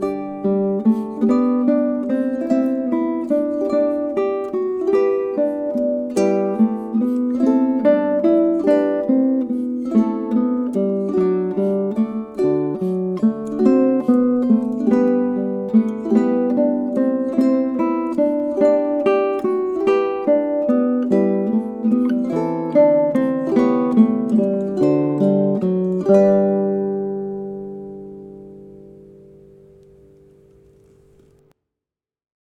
Due to the fast tempo of this piece, the simple strum 3/4 is recommended: strum once per measure on the downbeat.
Jesu_joy_melody_strums_short_ex.mp3